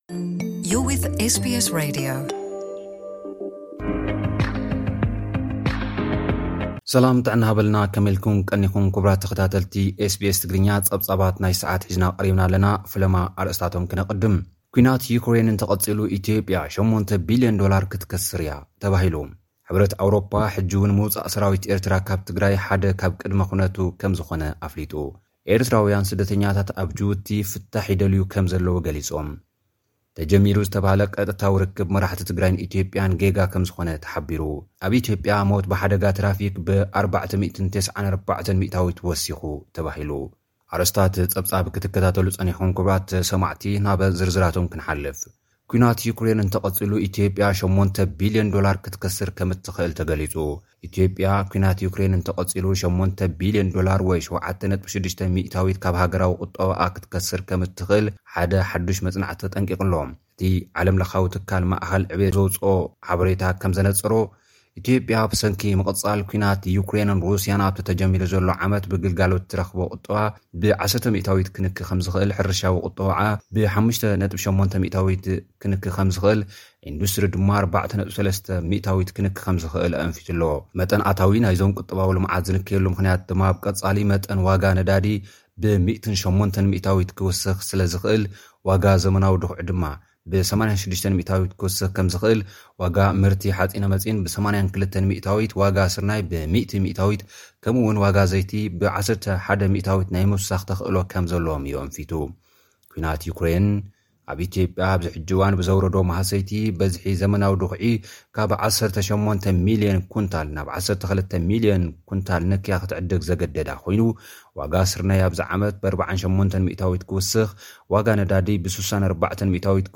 ኣብ ጸገም ዝርከቡ ኤርትራውያን ኣብ ጁቡቲ ፍታሕ ክናደለዮም ተማሕጺኖም። (ጸብጻብ)